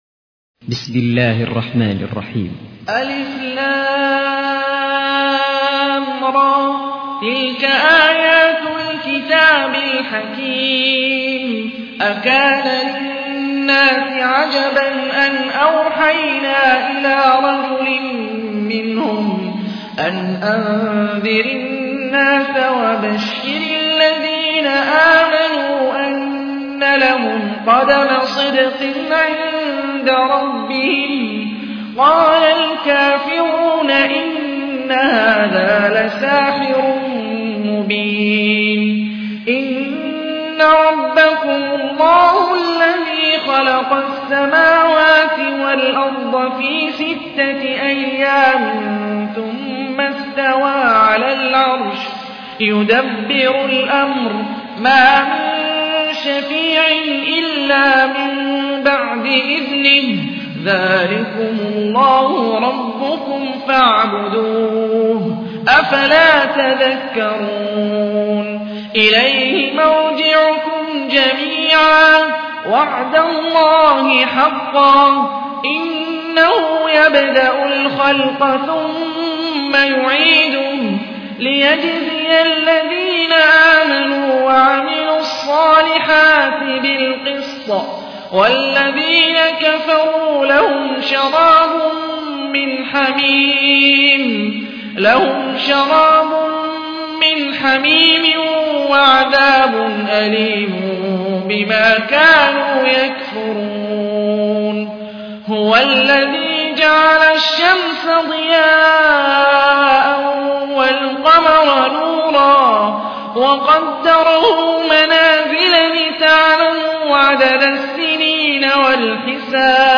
تحميل : 10. سورة يونس / القارئ هاني الرفاعي / القرآن الكريم / موقع يا حسين